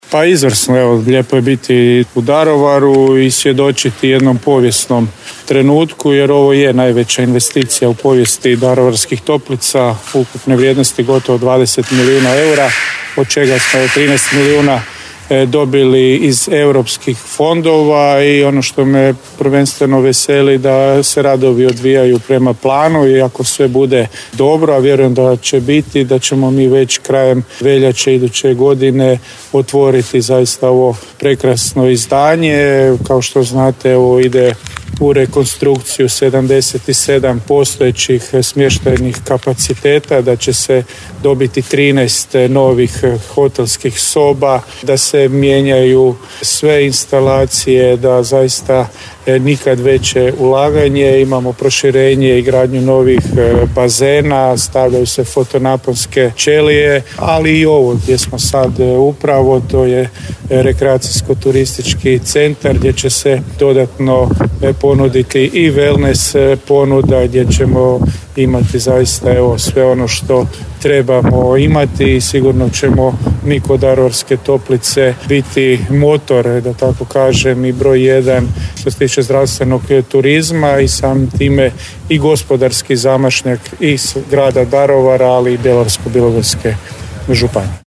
Nakon što je obišao radove o investiciji vrijednoj skoro 20 milijuna eura, župan Marko Marušić rekao je: